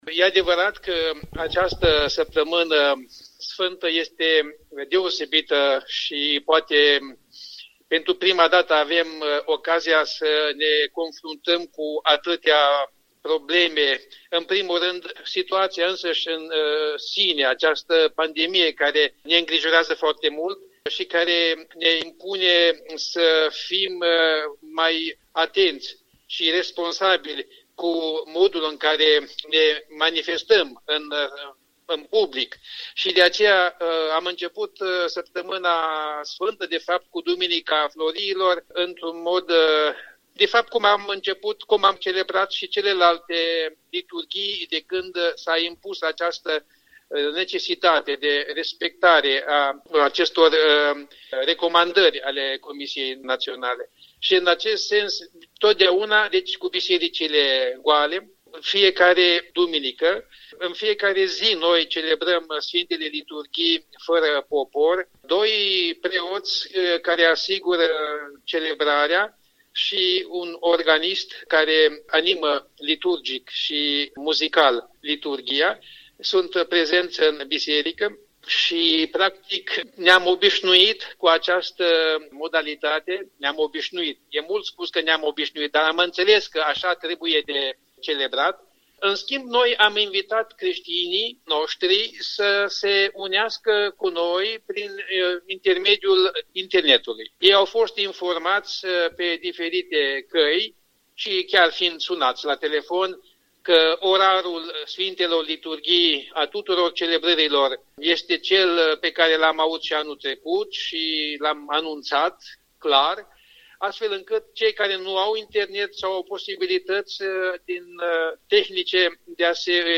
Interviu cu PĂPS Anton Coșa, despre Paștele catolic, celebrat la distanță și cu grija de a respecta toate restricțiile impuse pe timp de stare de urgență.
PS Anton Coșa, episcop al diecezei romano-catolice din Chișinău.